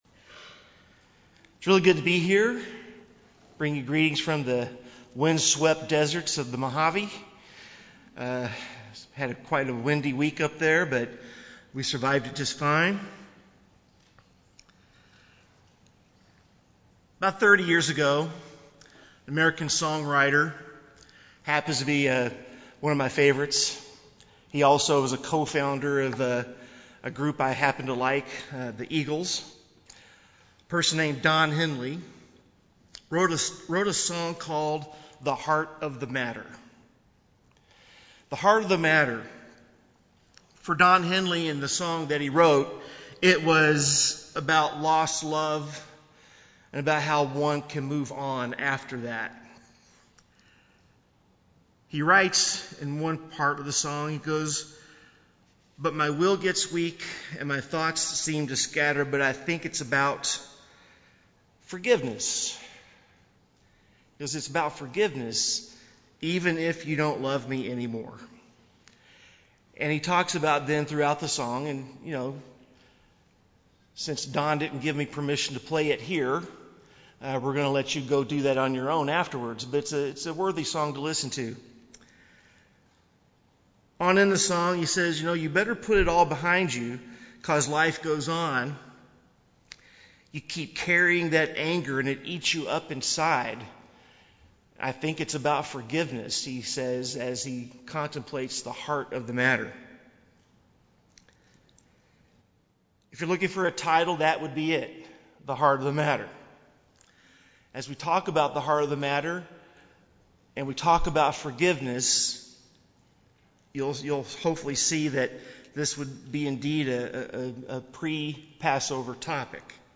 This pre-Passover message focuses on forgiveness. Out of great love for us, Jesus Christ willingly became the atoning sacrifice through whom we are offered forgiveness, redemption and personal reconciliation with God. We, also, are required to forgive others.